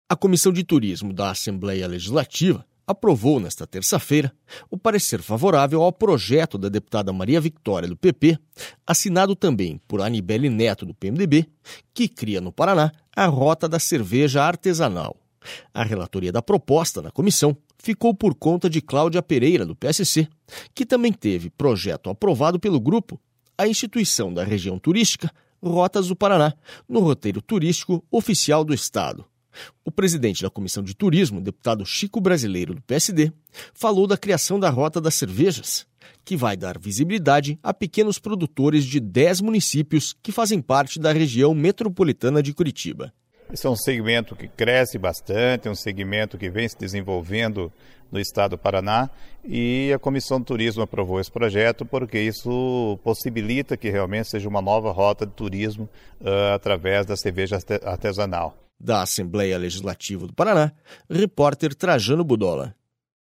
SONORA CHICO BRASILEIRO